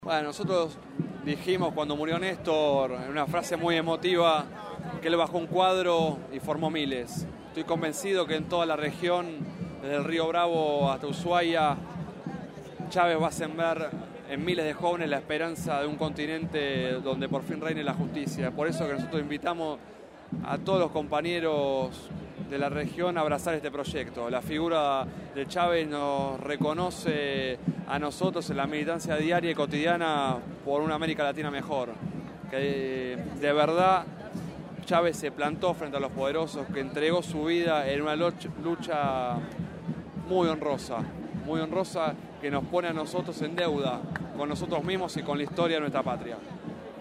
Radio Gráfica se hizo presente y entrevistó a militantes políticos, periodistas y referentes de la juventud para transmitir testimonio de la significancia del gran revolucionario de éste siglo.